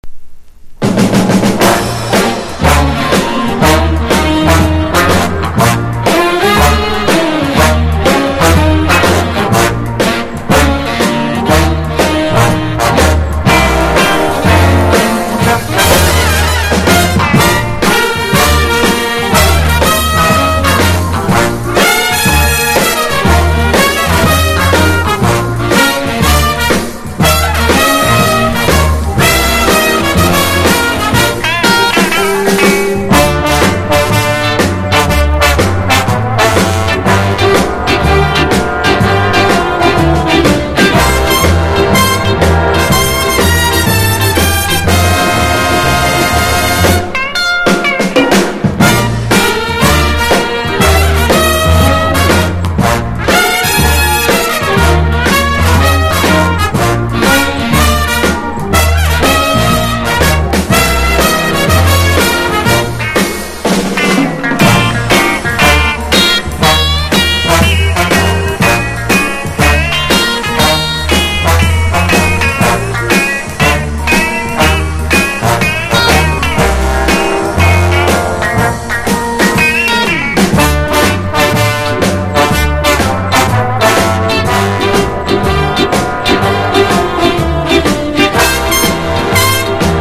FUSION / JAZZ ROCK# FRENCH / EURO POPS